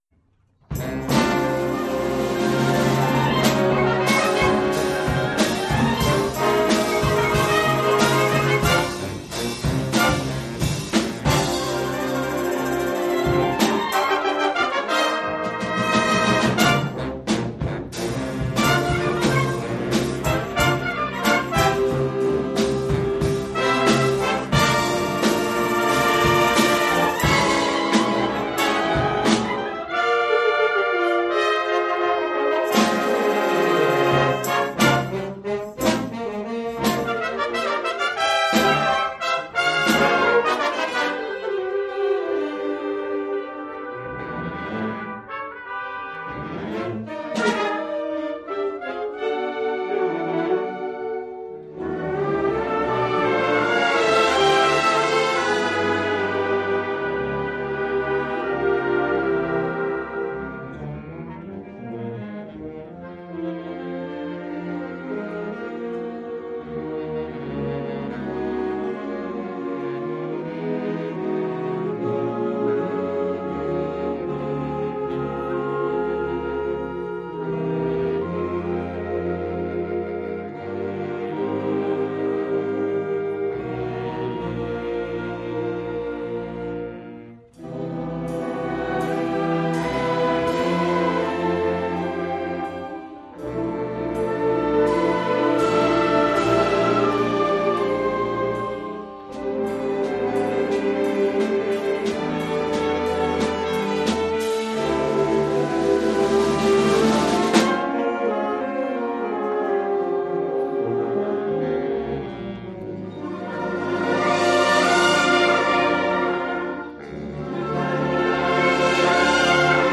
Genre musical : Classique
Oeuvre pour orchestre d’harmonie.